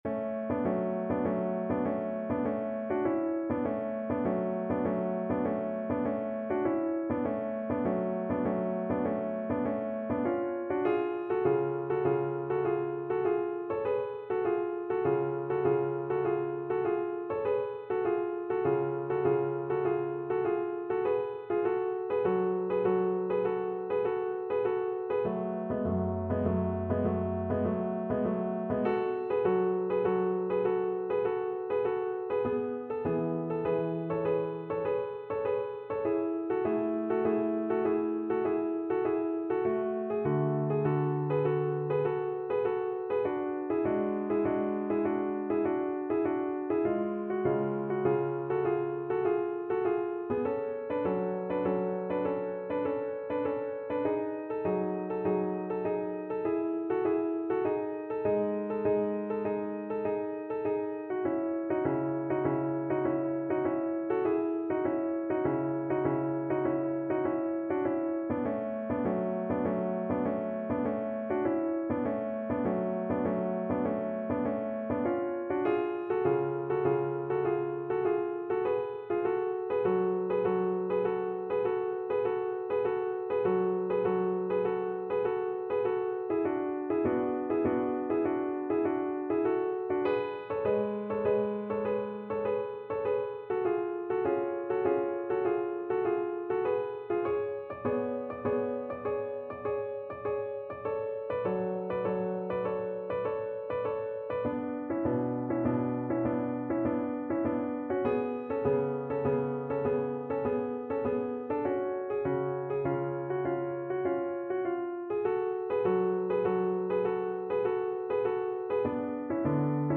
Classical Vivaldi, Antonio Spring from the Four Seasons, Second Movement (Largo) Cello version
Play (or use space bar on your keyboard) Pause Music Playalong - Piano Accompaniment Playalong Band Accompaniment not yet available transpose reset tempo print settings full screen
Cello
F# minor (Sounding Pitch) (View more F# minor Music for Cello )
Largo
3/4 (View more 3/4 Music)
Classical (View more Classical Cello Music)